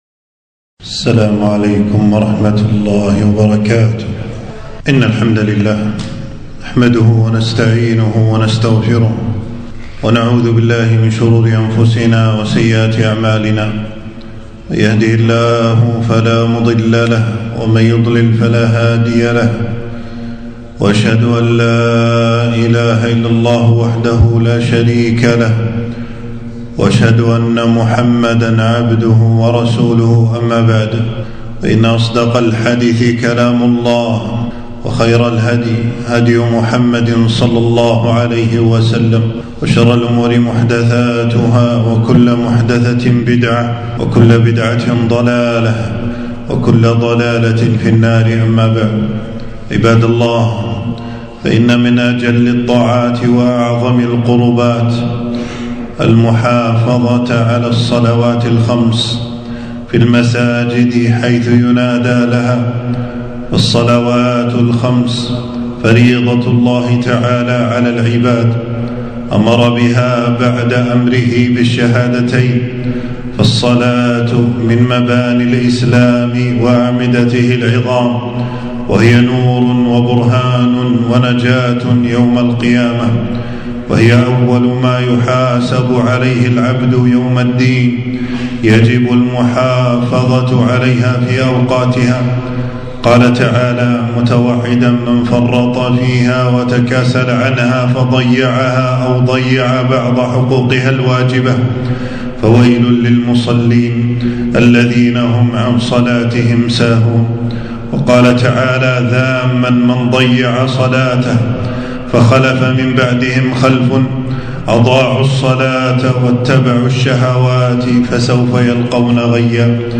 خطبة - فويل للمصلين الذين هم عن صلاتهم ساهون